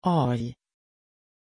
Aussprache von Shay
pronunciation-shay-sv.mp3